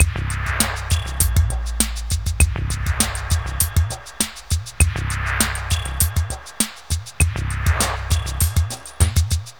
Downtempo 07.wav